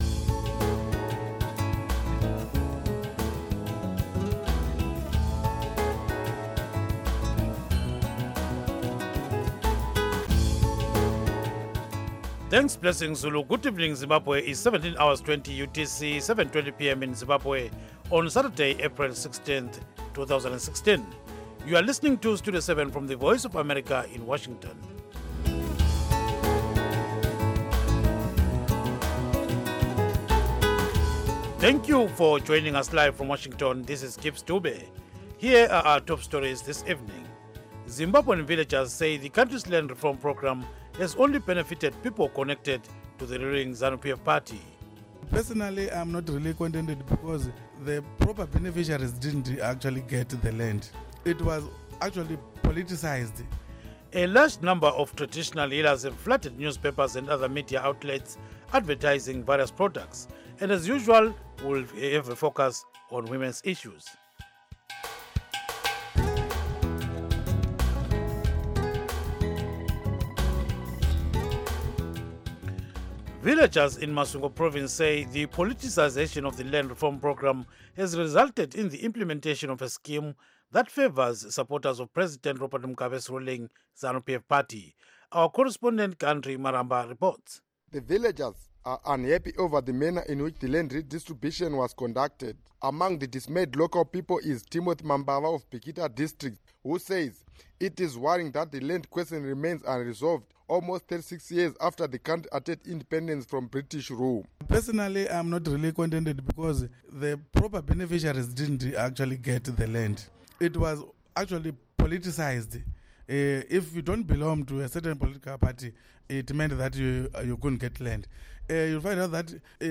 News in English